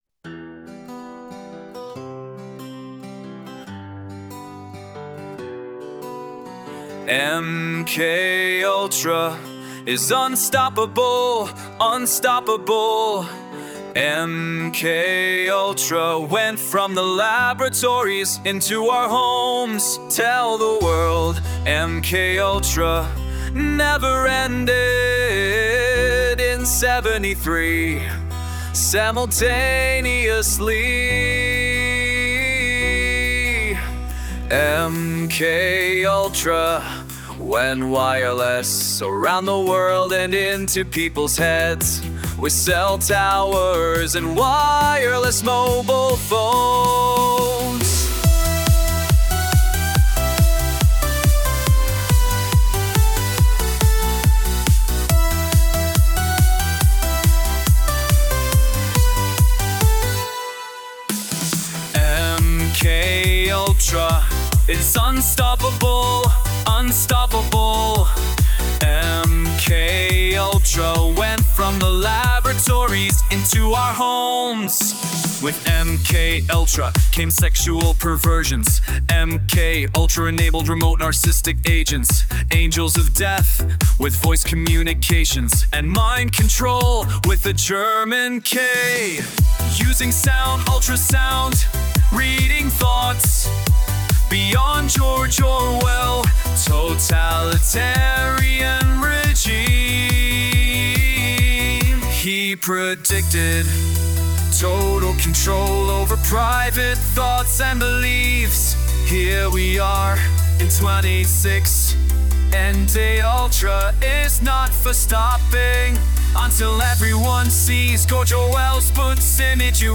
POPULAR - POP ROCK